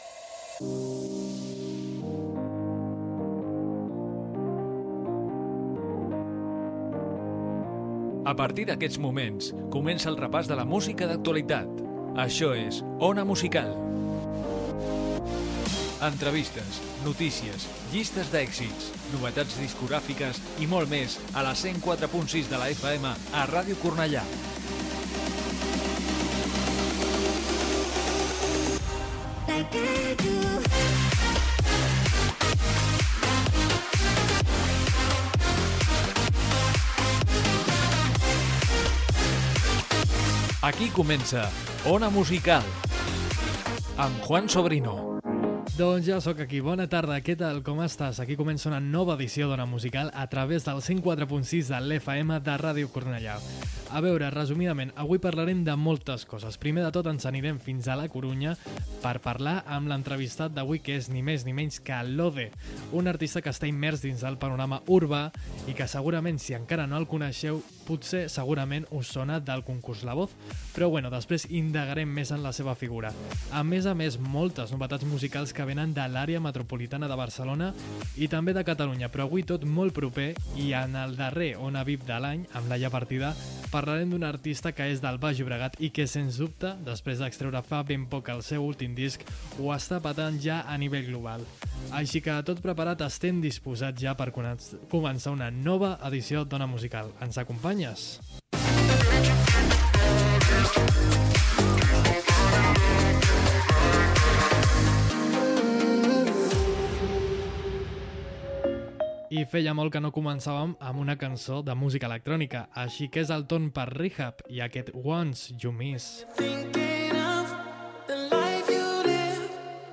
Careta del programa, presentació, sumari, tema musical, indicatiu del programa
Gènere radiofònic
Musical